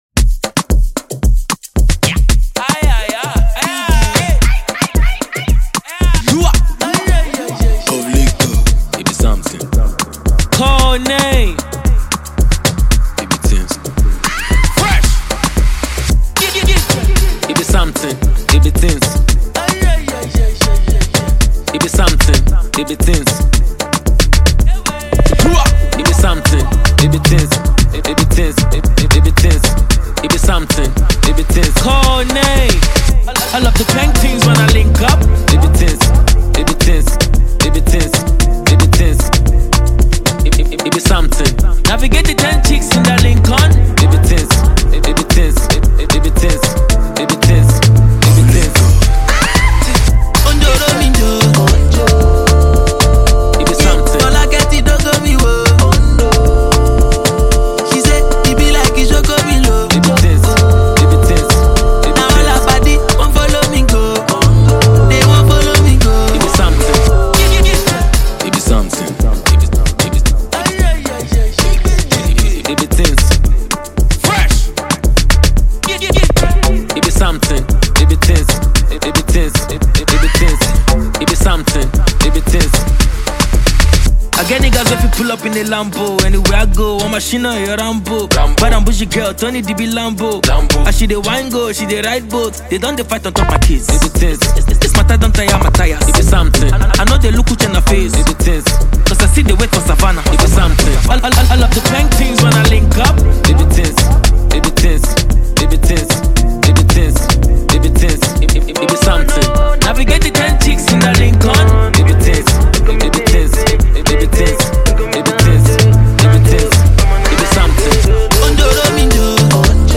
rap artist
Amapiano